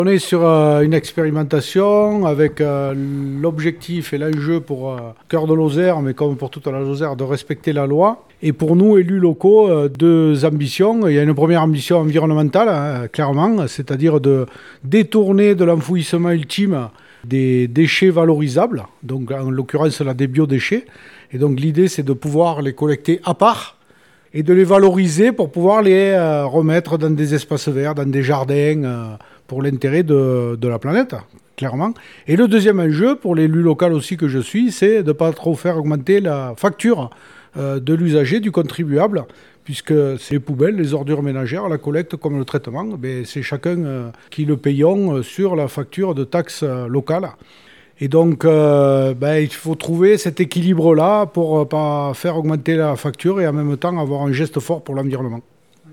Laurent Suau président de la communauté de communes cœur de Lozère.